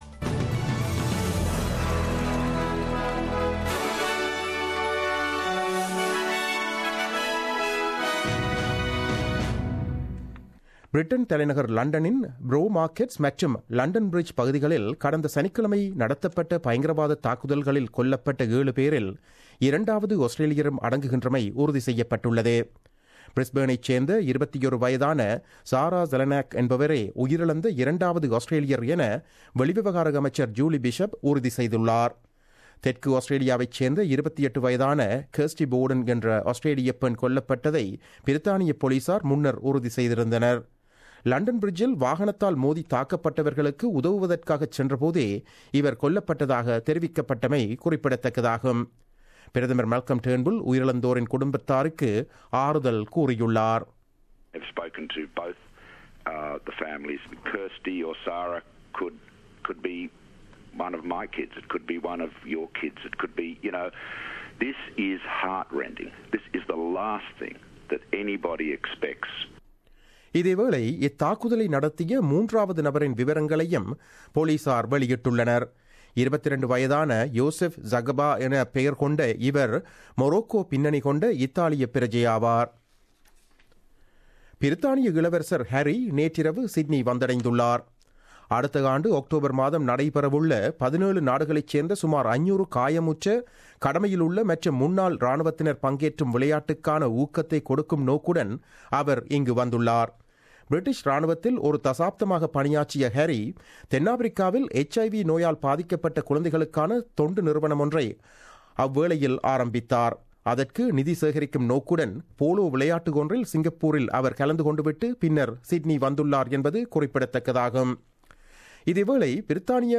The news bulletin broadcasted on 7 June 2017 at 8pm.